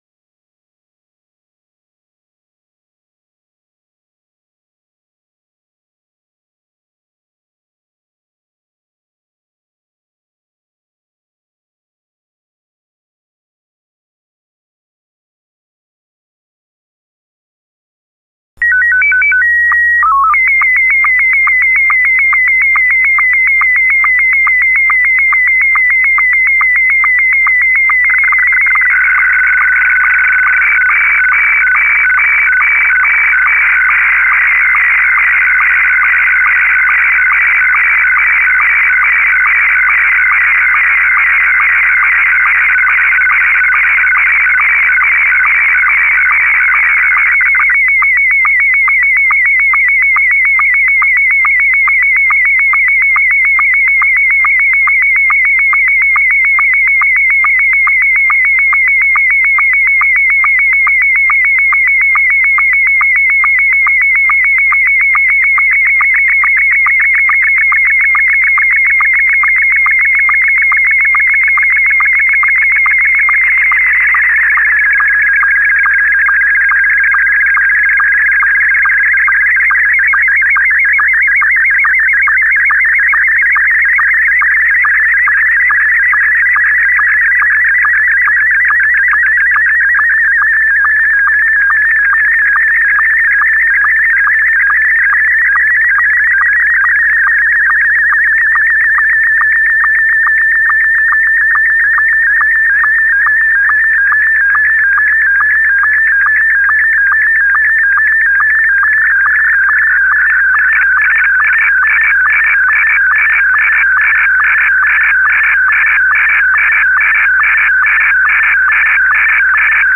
Unlike traditional video transmission, which requires high data rates, SSTV sends images line by line as modulated audio tones, making it ideal for long-distance and low-power communications.
SSTV signals operate within the audio frequency range, typically between 1500 and 2300 Hz.
It works by encoding each line of the image as a sequence of tones, which can be transmitted via radio and decoded back into a picture using software or hardware tools.